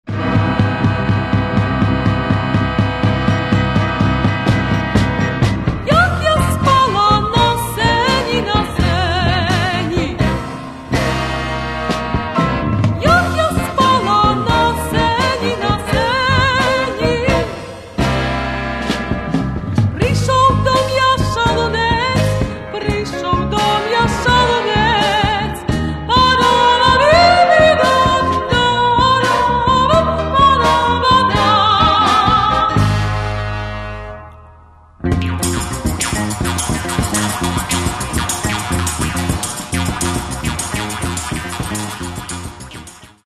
К сожалению, качество записи некоторых песен не безупречно.
музыка: українська народна пісня